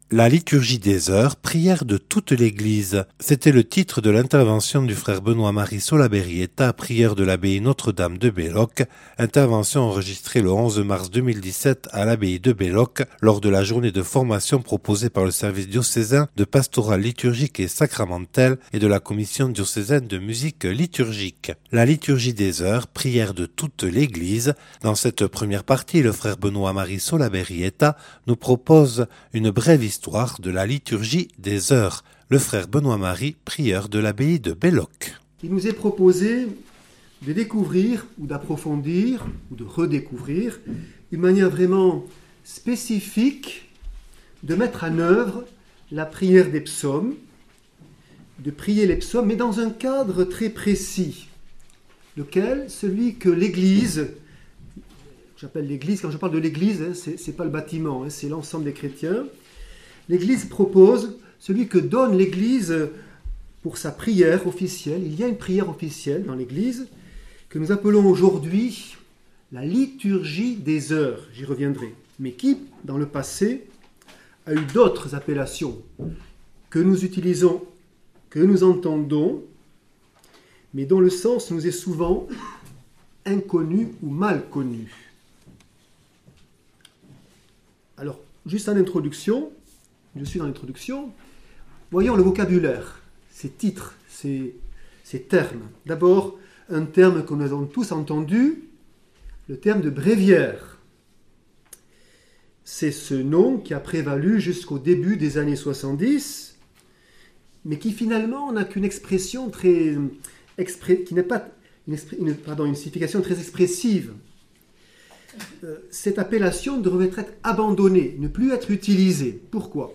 (Enregistré le 11/03/2017 à Belloc lors de la journée de formation proposée par le Service diocésain de Pastorale Liturgique et Sacramentelle et la Commission diocésaine de Musique liturgique).